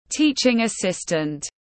Trợ giảng tiếng anh gọi là teaching assistant, phiên âm tiếng anh đọc là /ˈtiː.tʃɪŋ əˌsɪs.tənt/.
Teaching assistant /ˈtiː.tʃɪŋ əˌsɪs.tənt/
Để đọc đúng tên tiếng anh của trợ giảng rất đơn giản, các bạn chỉ cần nghe phát âm chuẩn của từ teaching assistant rồi nói theo là đọc được ngay.